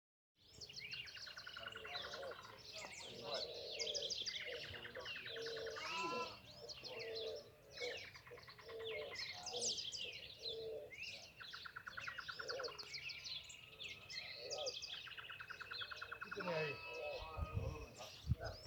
Chotoy (Schoeniophylax phryganophilus)
Nombre en inglés: Chotoy Spinetail
Localización detallada: Ruta provincial 43
Condición: Silvestre
Certeza: Observada, Vocalización Grabada